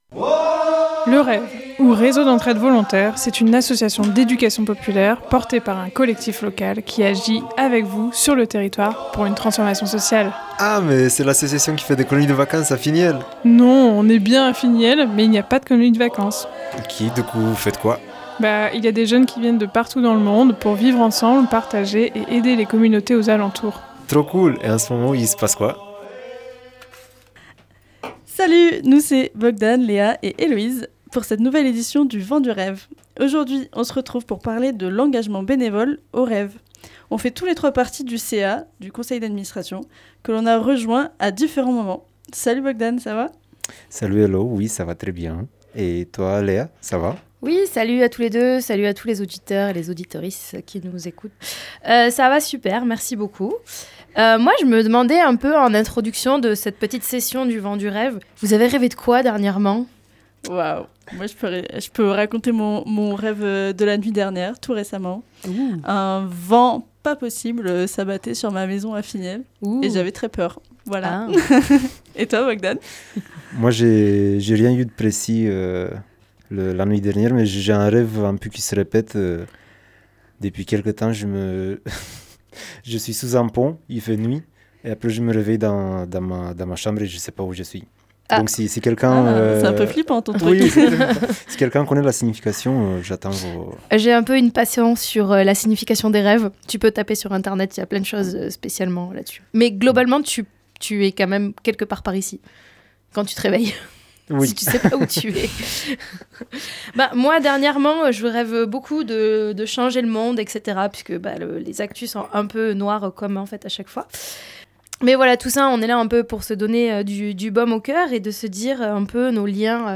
Les membres du CA du REV se sont rejoins dans le studio de Bartas pour se présenter et faire un point sur l'asso.
Aujourd’hui c’est une partie des membres du CA du REV qui sont en studio pour se présenter !